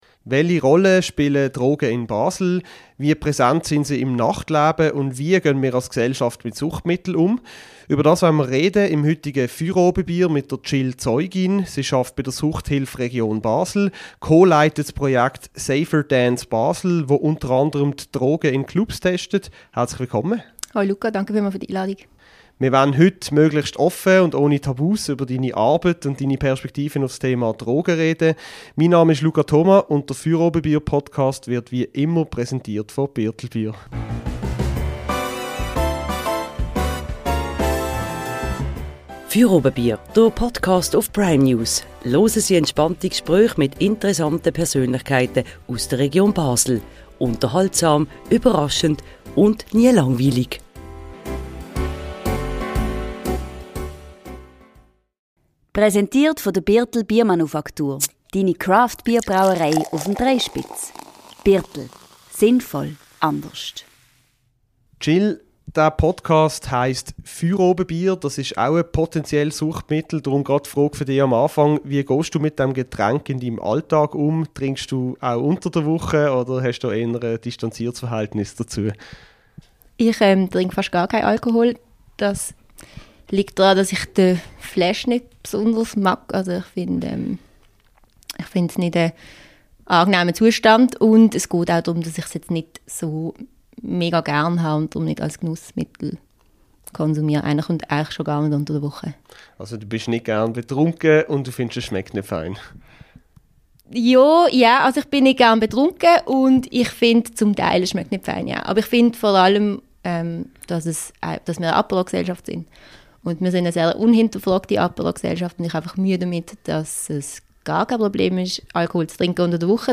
Moderation